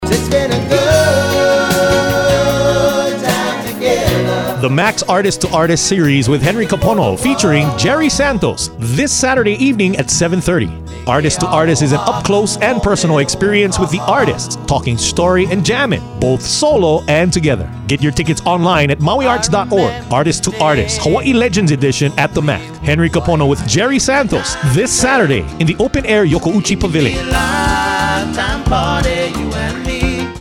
laid-back contemporary rock
blending Hawaiian music with contemporary rhythms.